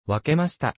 Japanese Unit Voice
Japanese unit responses.
And couldn't resist making some Japanese unit voices with it.